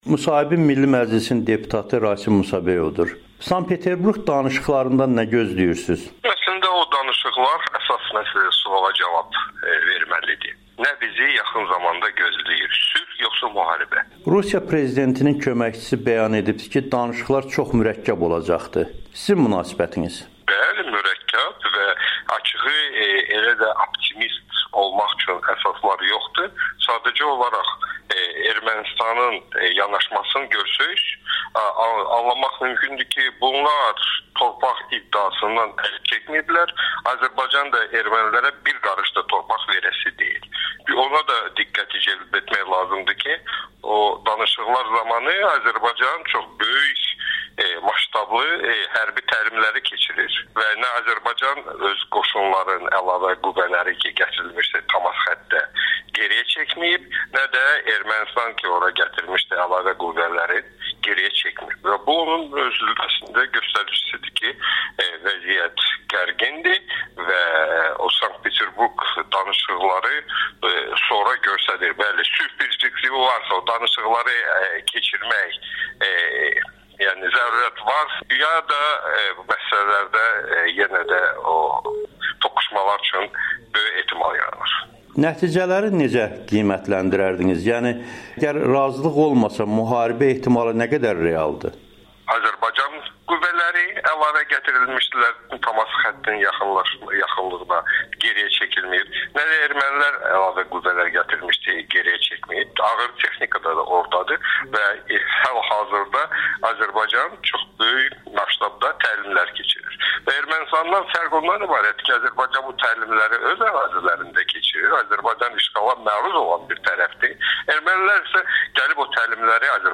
Milli Məclisin deputatı Sankt-Peterburq görüşü barədə Amerikanın Səsinə müsahibə verib
Milli Məclisin deputatı Rasim Musabəyovun Amerikanın Səsinə müsahibəsi